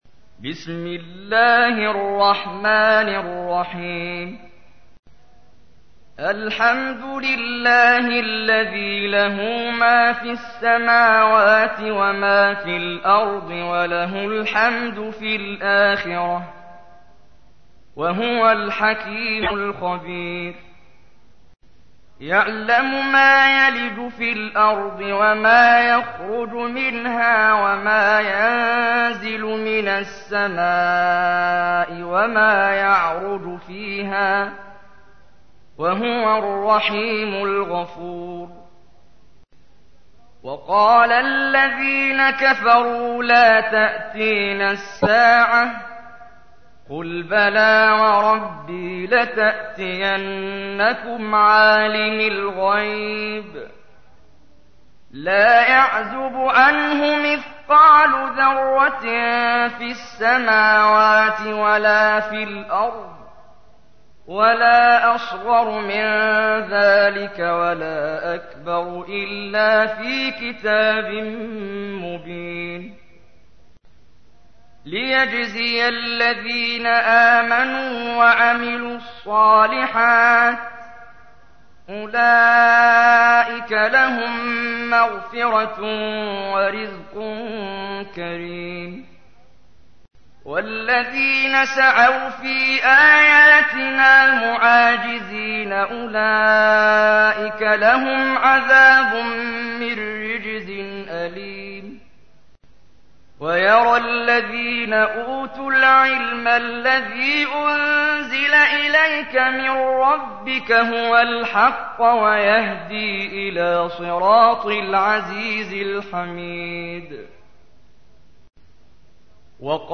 تحميل : 34. سورة سبأ / القارئ محمد جبريل / القرآن الكريم / موقع يا حسين